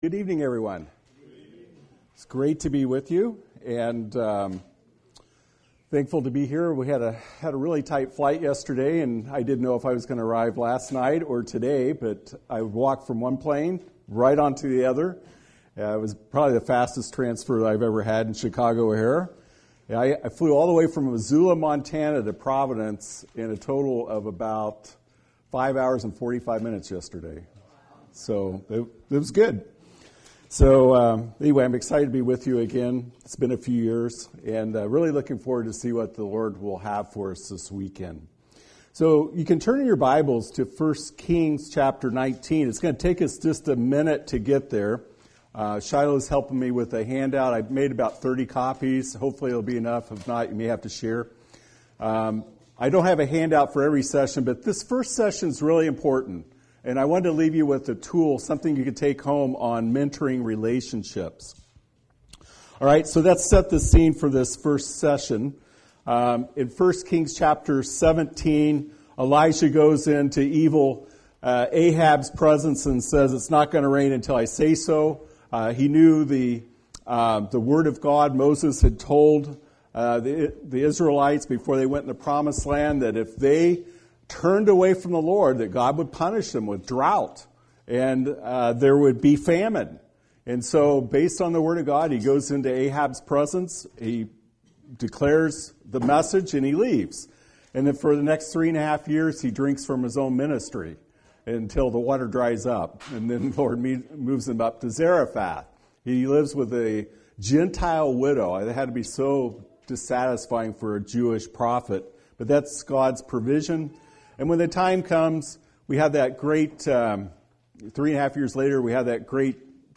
Series: Spring Bible Conference